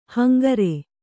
♪ hangare